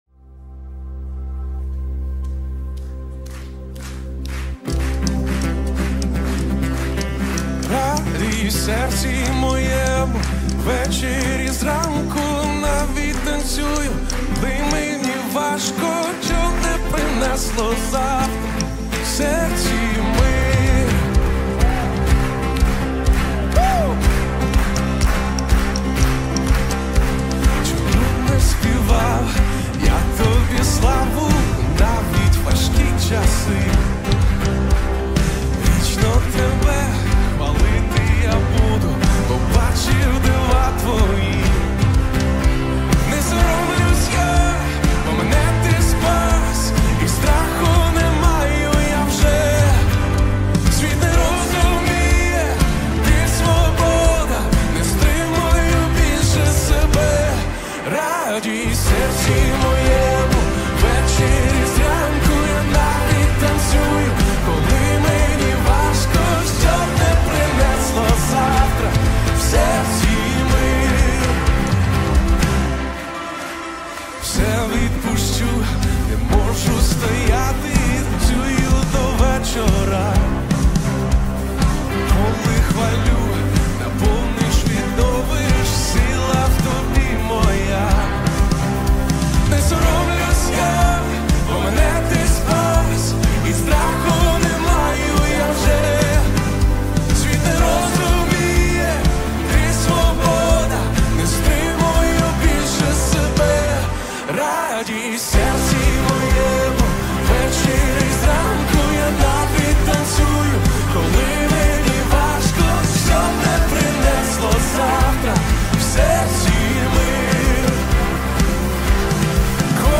1037 просмотров 410 прослушиваний 30 скачиваний BPM: 124